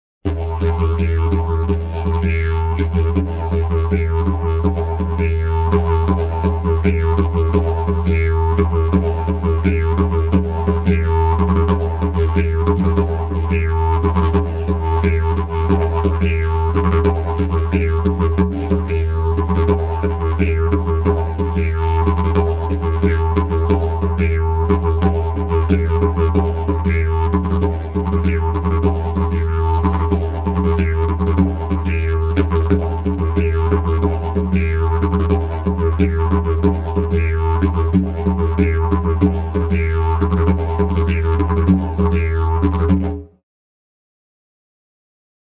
Sample n°38 contiene: applicazione del double tonguing.